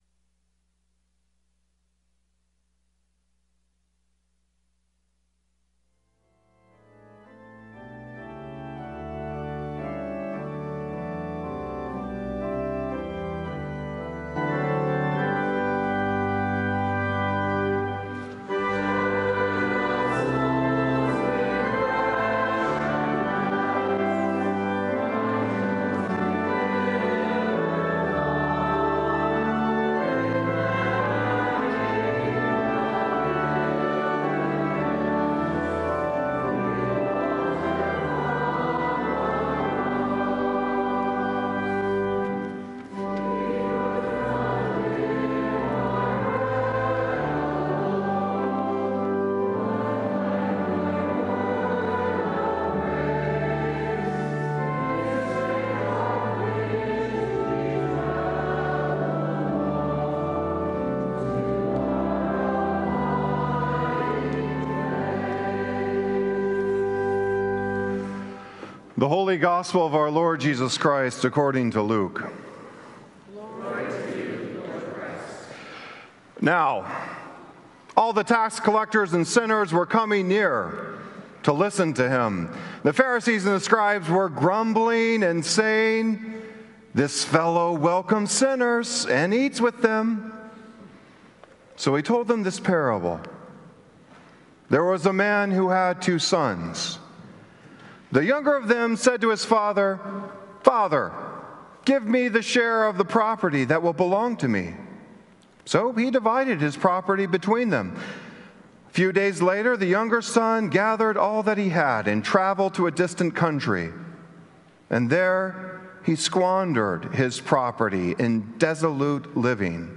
Sermons from St. Columba's in Washington, D.C.